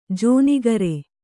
♪ jōnigare